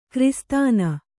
♪ kristāna